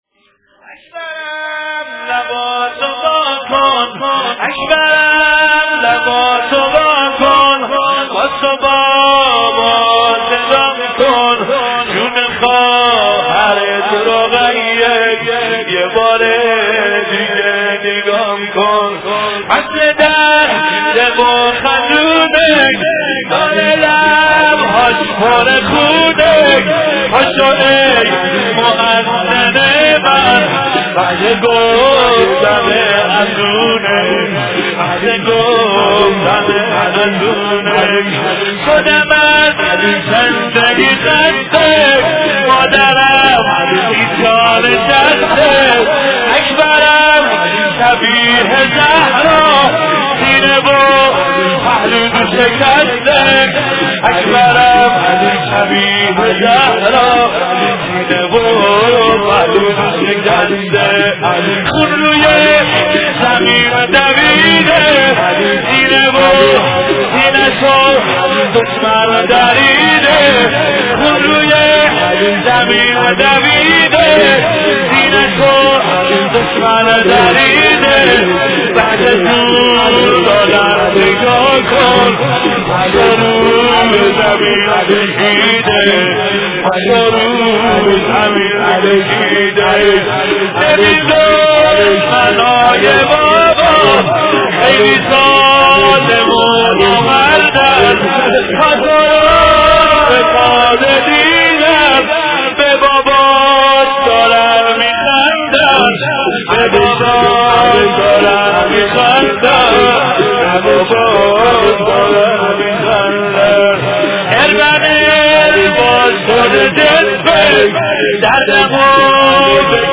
شور: